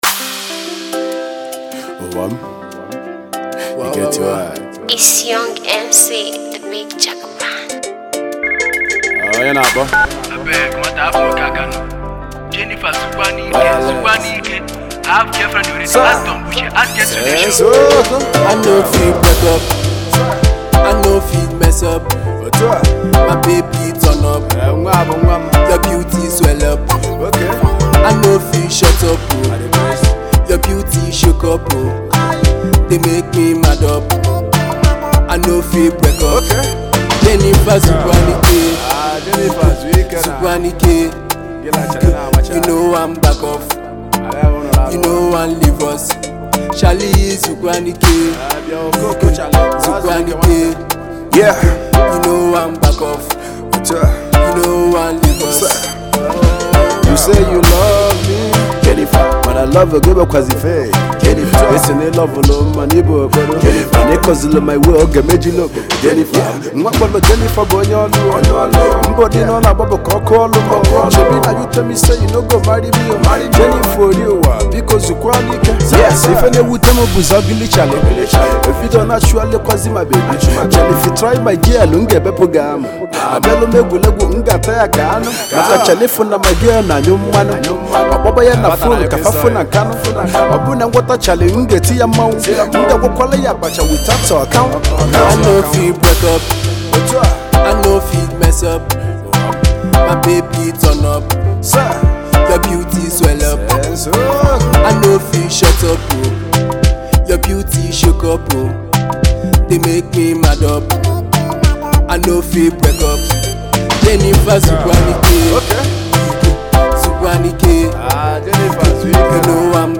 good rap verse